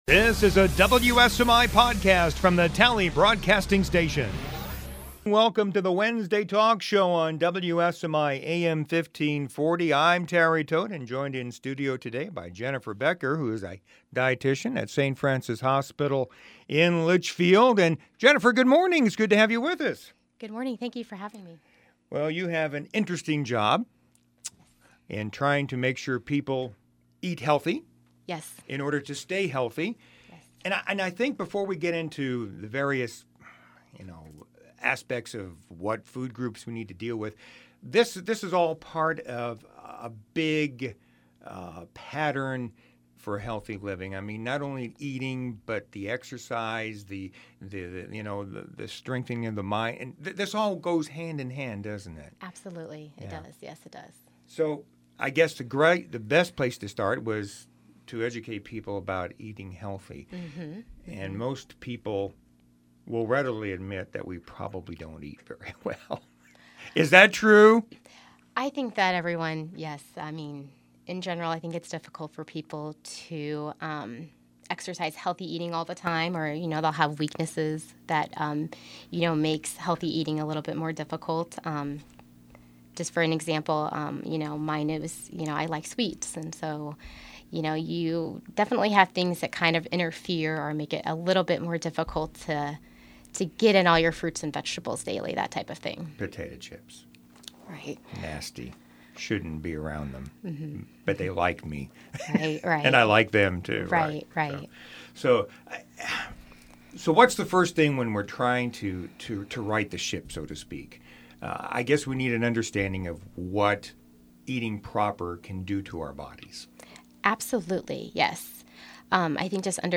09/30/2015 Wednesday Talk Show Guest